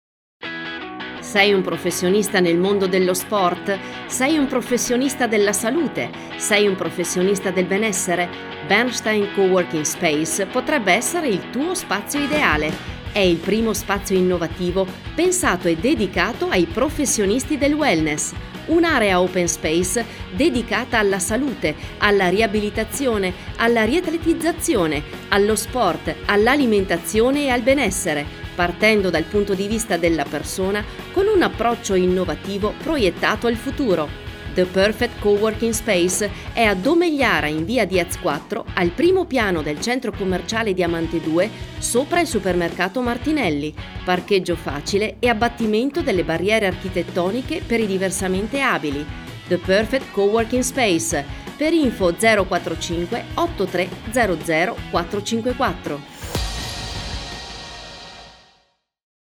SPOT RADIO: the-perfect-coworking-space-audio